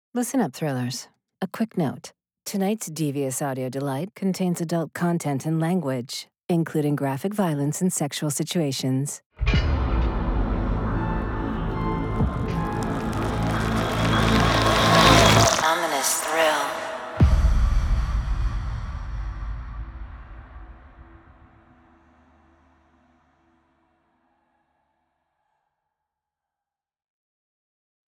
Wellness